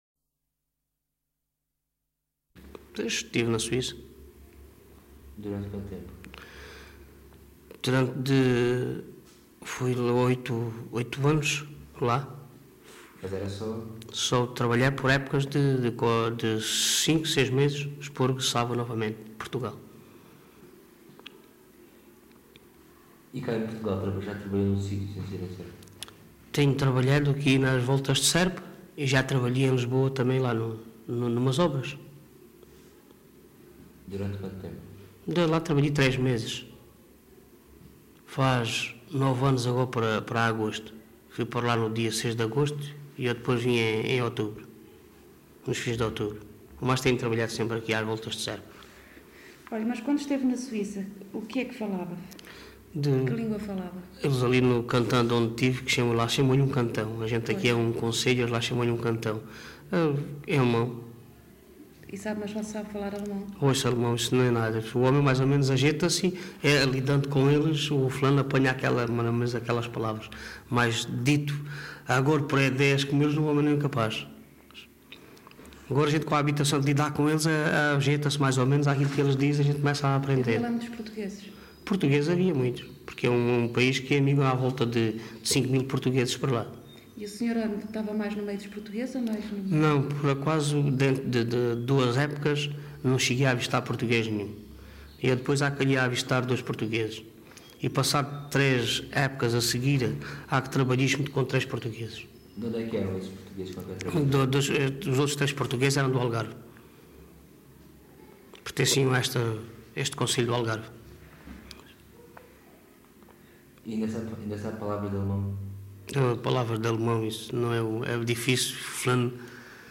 LocalidadeSerpa (Serpa, Beja)